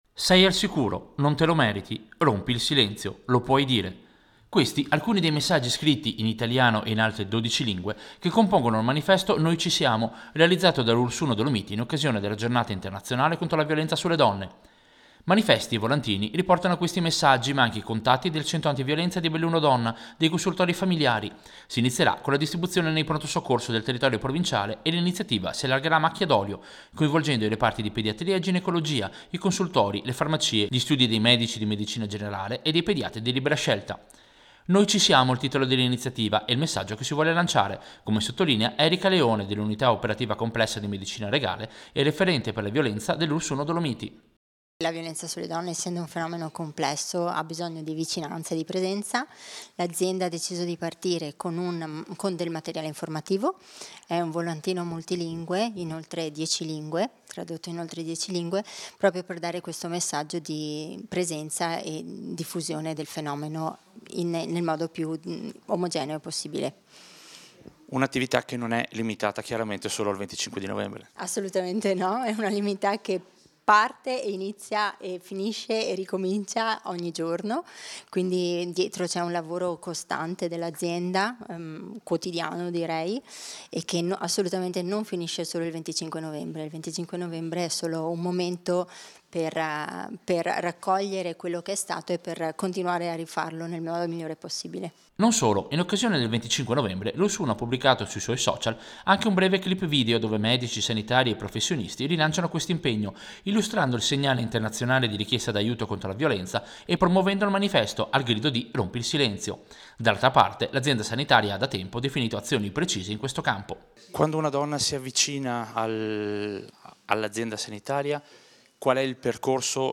Servizio-Ulss-Iniziativa-violenza-donne.mp3